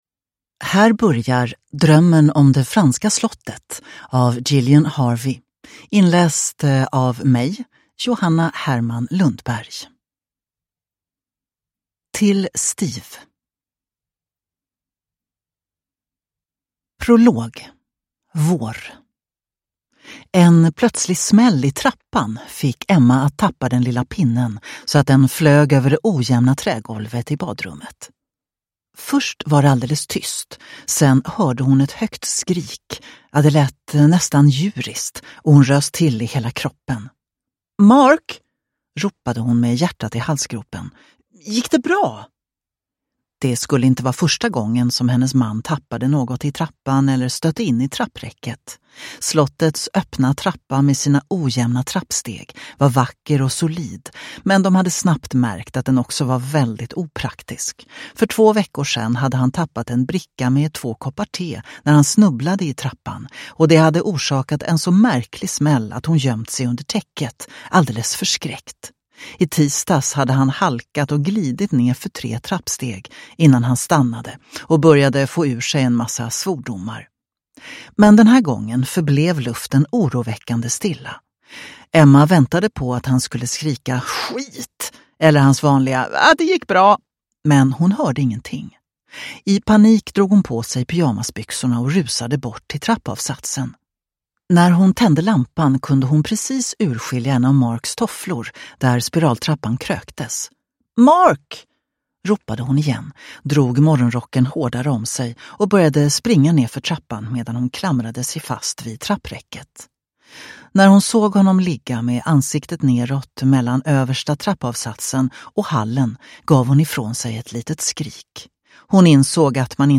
Drömmen om det franska slottet – Ljudbok